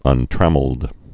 (ŭn-trăməld)